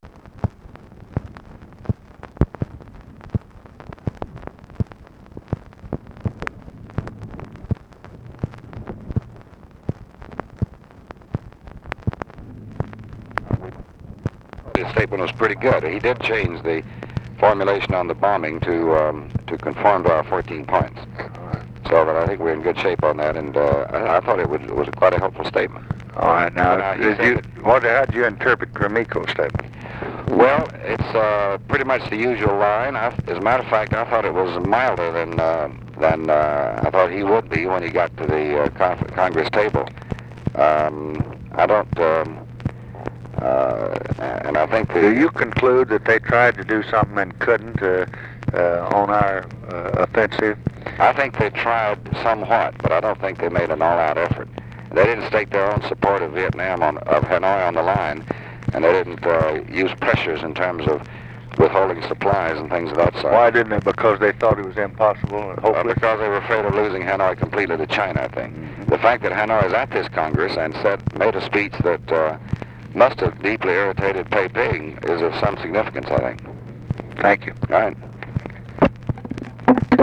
Conversation with DEAN RUSK, April 2, 1966
Secret White House Tapes